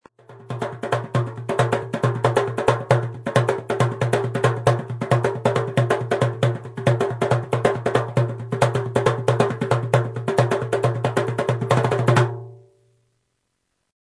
DERBAKE or DARBUKA
Sound sample of the Derbake
It has a very limited tonal range, but in countries like Egypt or Turkey has reached a very high level of virtuosity to her.
derbake.mp3